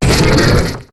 Cri de Gringolem dans Pokémon HOME.